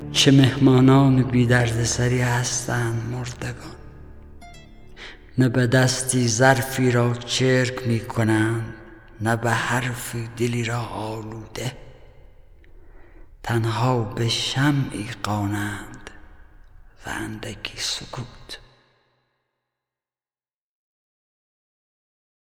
دانلود دکلمه سکوت با صدای حسین پناهی
گوینده :   [حسین پناهی]